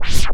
WIND SCRAT.2.wav